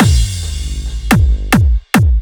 106 BPM Beat Loops Download